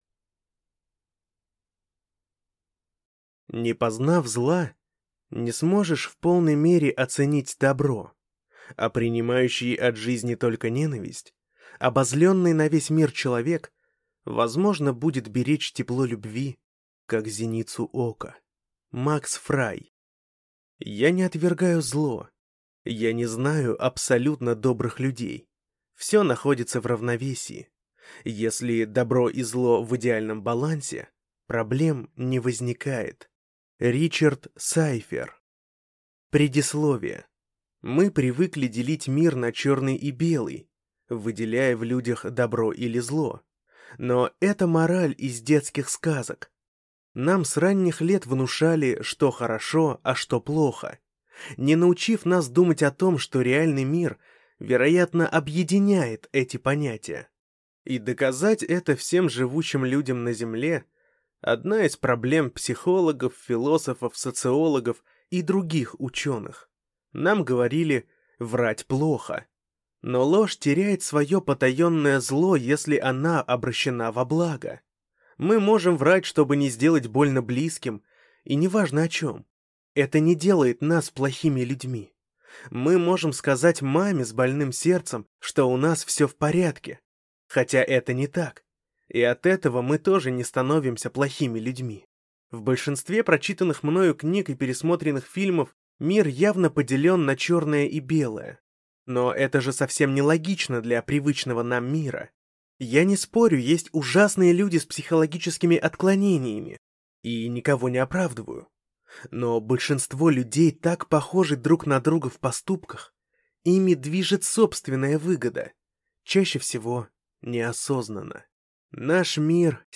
Аудиокнига На границе Вселенной. Кровавая корона | Библиотека аудиокниг